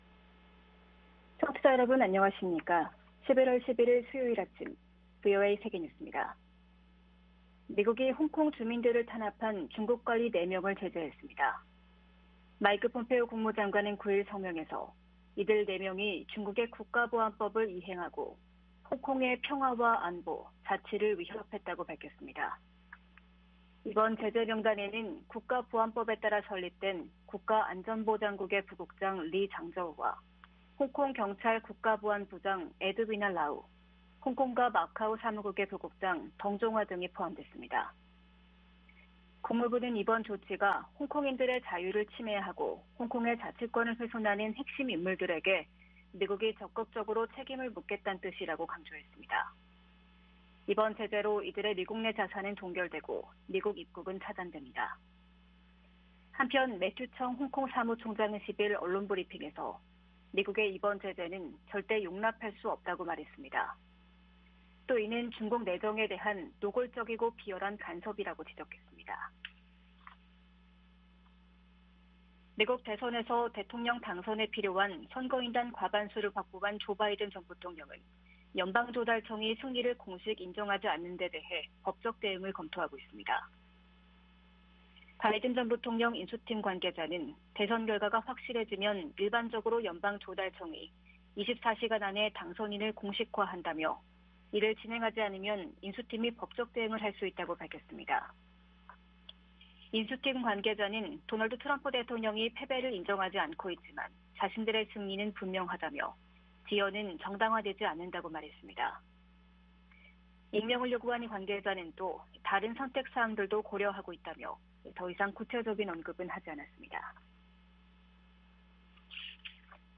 VOA 한국어 아침 뉴스 프로그램 '워싱턴 뉴스 광장' 2020년 11월 11일 방송입니다. 미국 민주당 조 바이든 전 부통령이 코로나 대응과 경제, 인종 문제 등 국내 현안을 최우선 순위로 정권 인수 준비 작업에 시동을 걸고 있습니다.